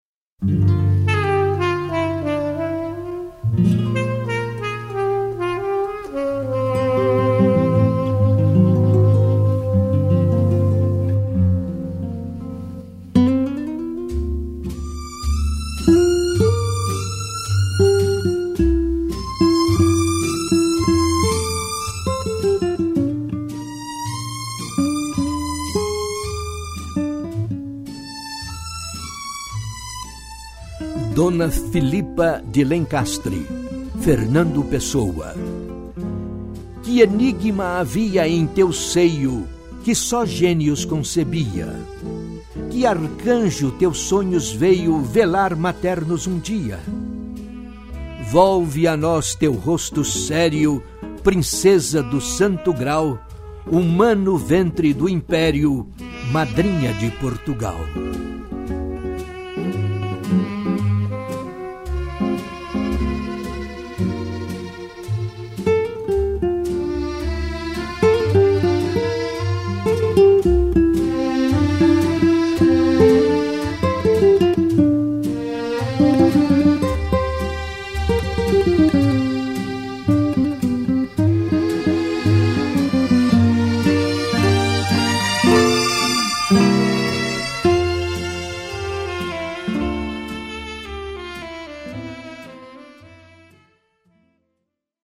Dona Philippa de Lencastre - declamação